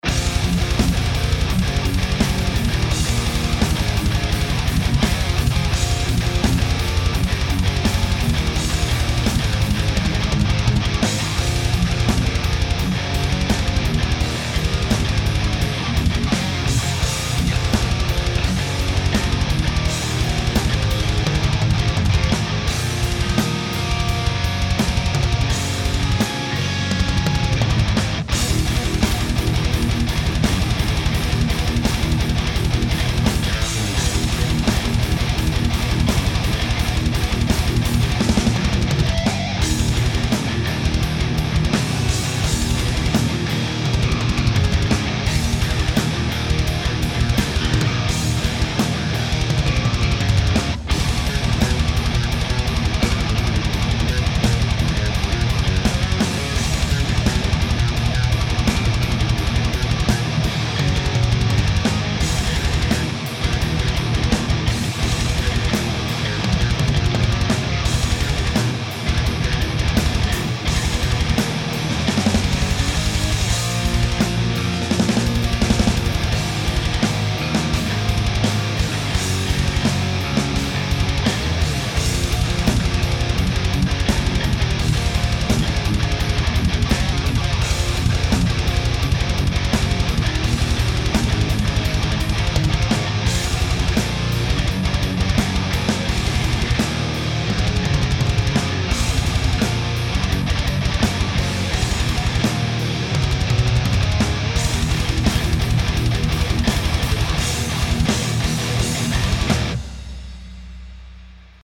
Just made a quick one with my old JCM900 (I wasn't sure it was in working order, apparently it is) boosted with a boss GE7 going into a 1960bx and mic'ed with my new Apex 210 (still trying to figure how to use that one although it's almost identical to my T-bone RB500)...